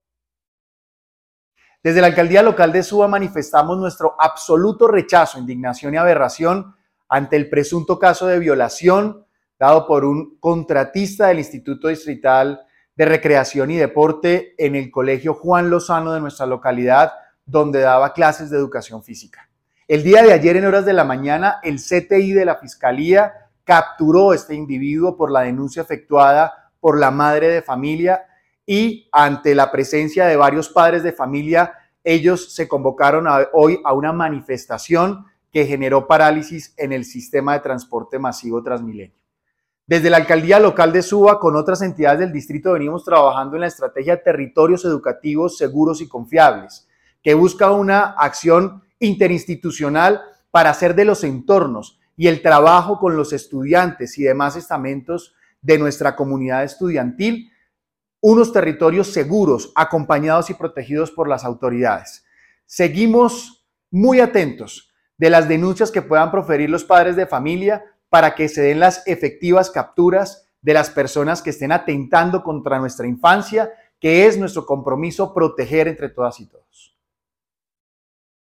palabras del alcalde Local Julián Andrés Moreno